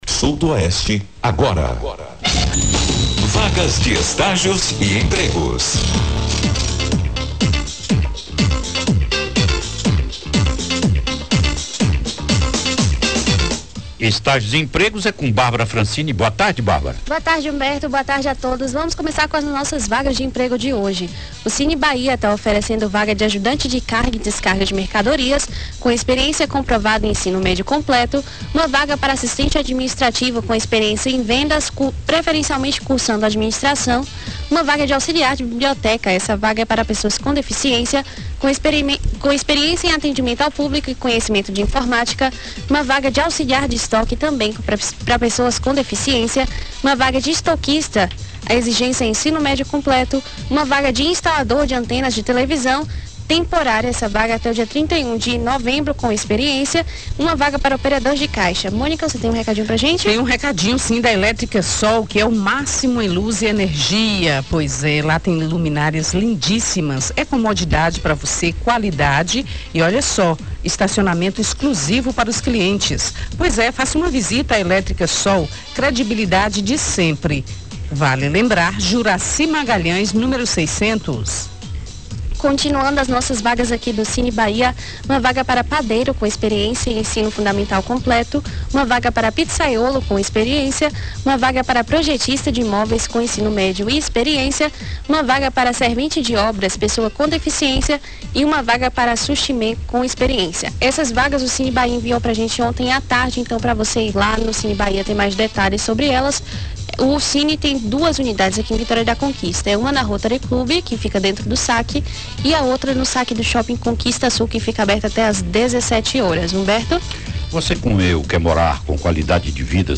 reportagem
que foi ao ar na Rádio Clube de Conquista reproduzido nesta terça-feira (30).